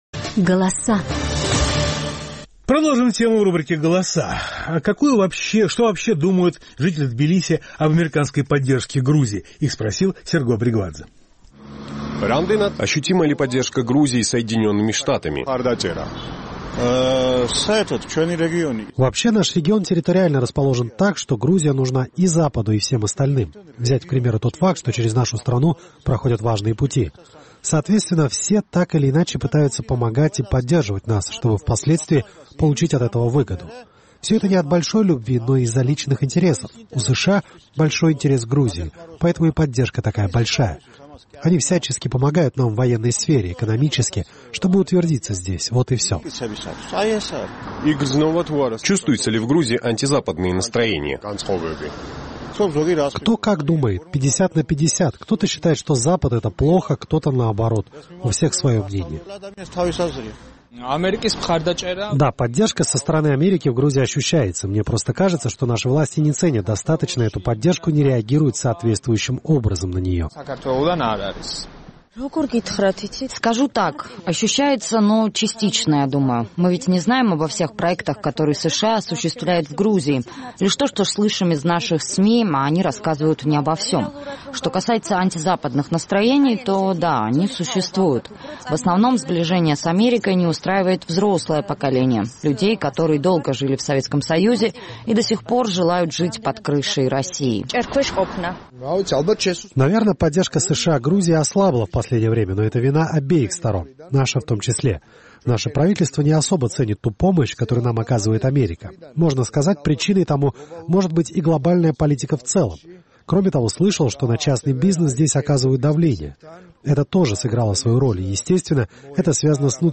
Наш тбилисский корреспондент обратился к горожанам с вопросом, ощутима ли, по их мнению, поддержка Грузии Соединенными Штатами.